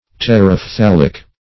Search Result for " terephthalic" : The Collaborative International Dictionary of English v.0.48: Terephthalic \Ter`eph*thal"ic\, a. [Terebene + phthalic.]